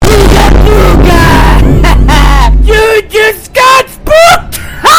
Stupid Fox Attacking Sound Button - Free Download & Play